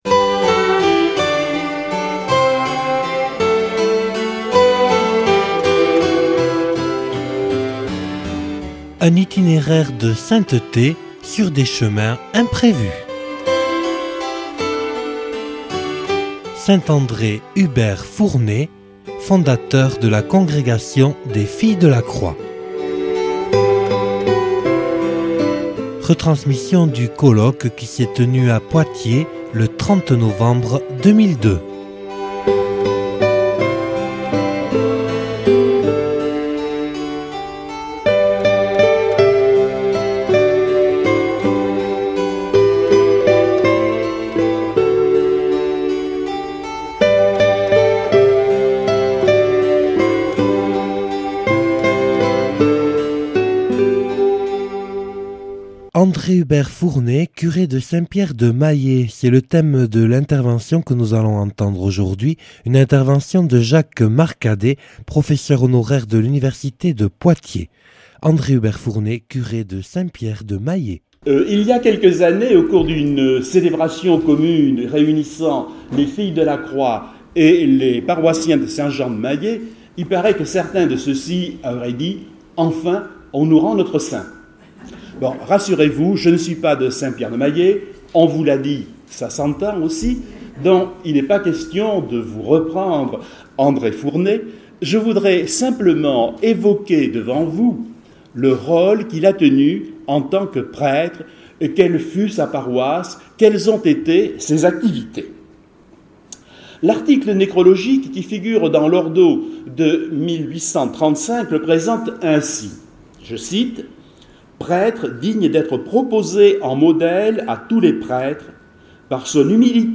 (Colloque enregistré le 30/11/2002 à Poitiers).